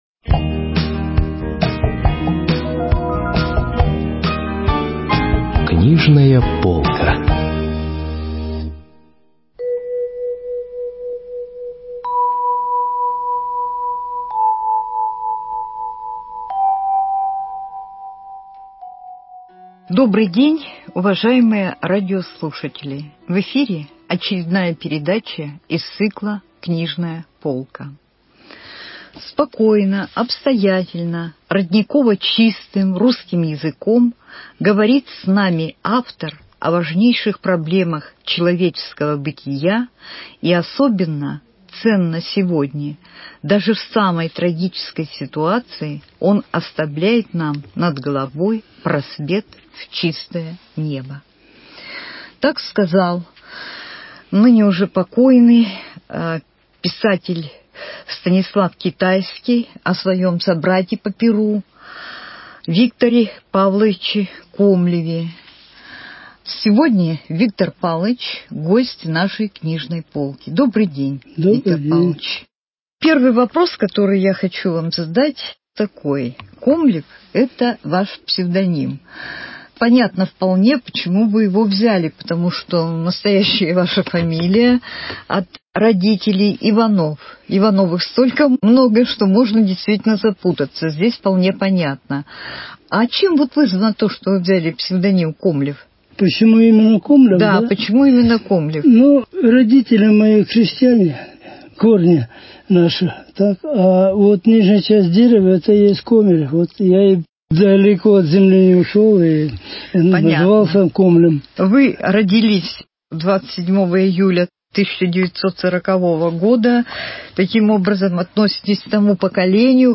беседует с прозаиком, публицистом, членом Союза писателей России